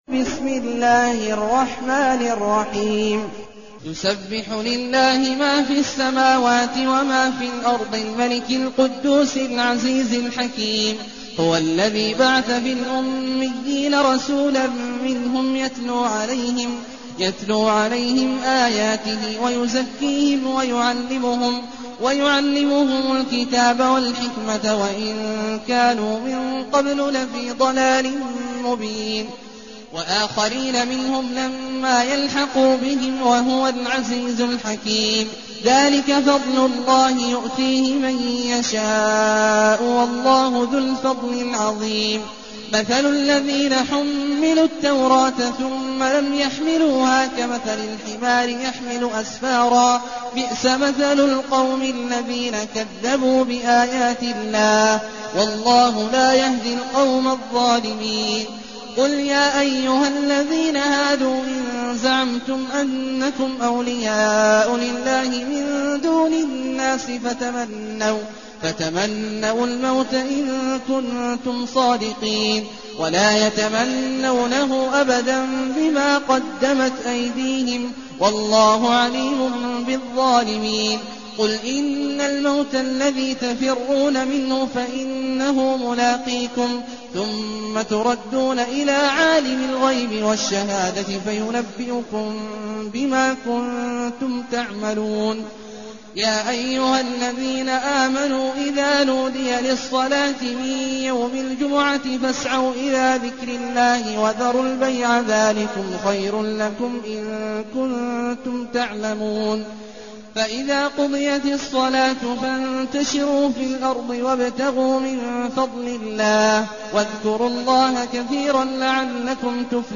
المكان: المسجد النبوي الشيخ: فضيلة الشيخ عبدالله الجهني فضيلة الشيخ عبدالله الجهني الجمعة